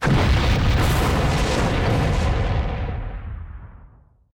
otherdestroyed6.wav